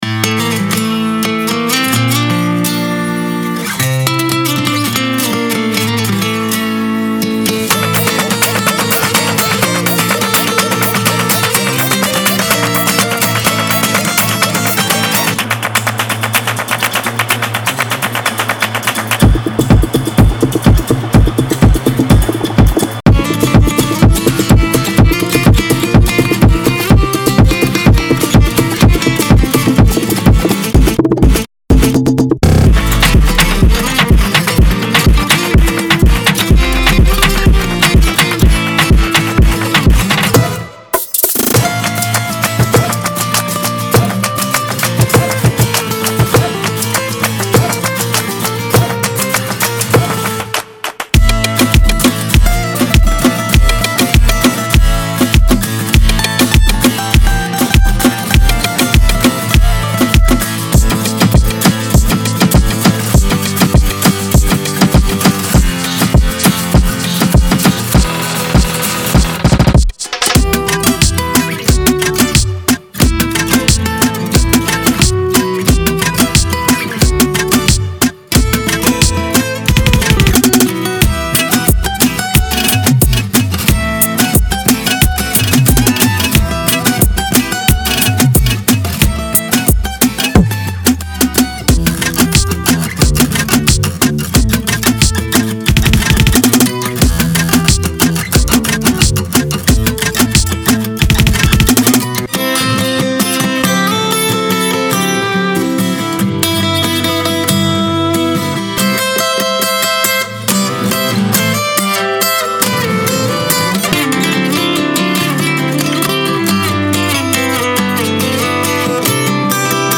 このギターは真にアナログ感を持ち、デジタルトラックにも命を吹き込みます。
デモサウンドはコチラ↓
Genre:Reggaeton
Guitar Loops \ Dry \ Full Mix \ No Solo \ Solo
Flamenco Guitar Loops (83 bpm) – 36